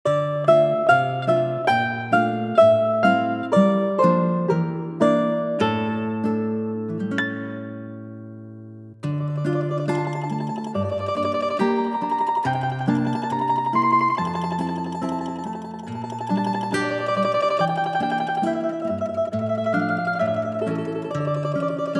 Звук: самостоятельная игра на домре